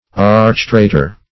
Search Result for " archtraitor" : The Collaborative International Dictionary of English v.0.48: Archtraitor \Arch`trai"tor\, n. [Pref. arch- + traitor.] A chief or transcendent traitor.
archtraitor.mp3